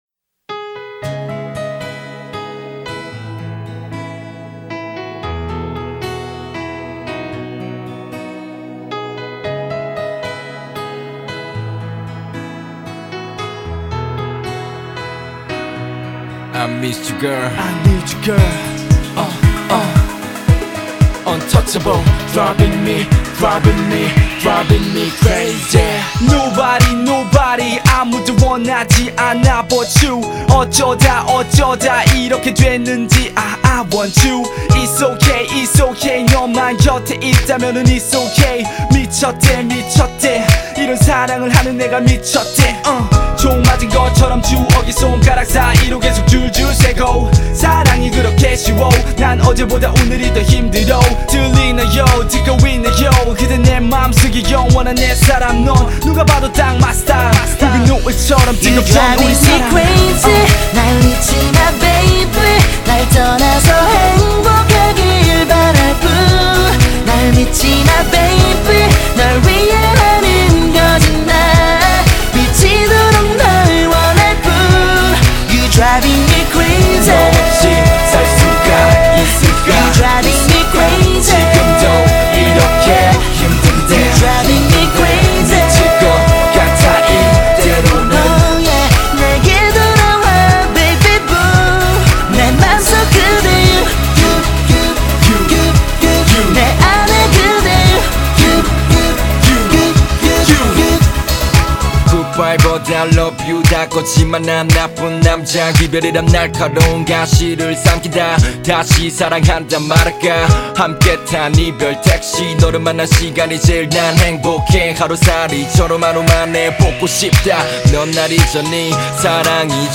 pop sounding music